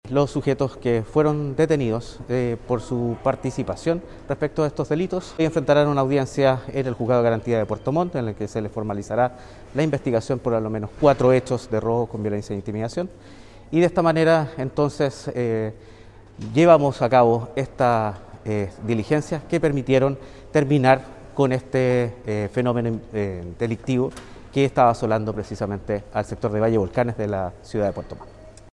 El fiscal Marcelo Sambucetti, a cargo de las pesquisas confirmó que los detenidos serán puestos a disposición del Tribunal de Garantía de Puerto Montt